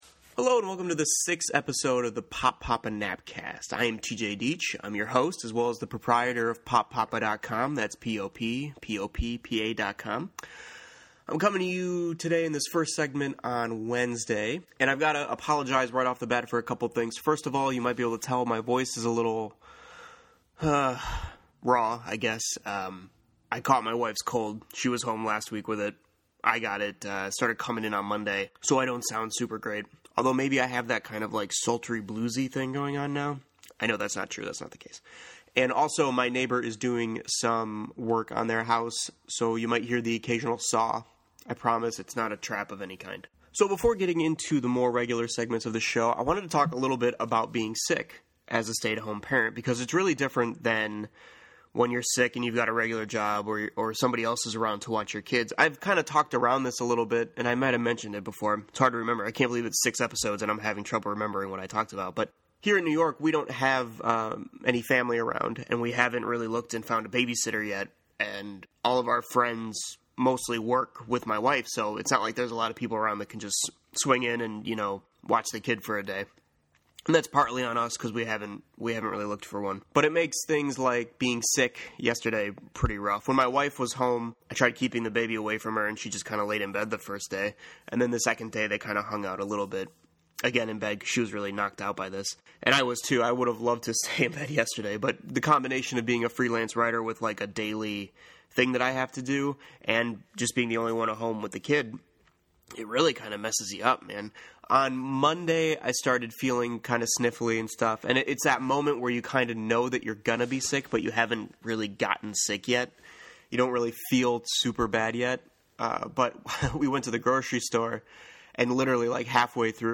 Recorded while fighting through a sore throat and cold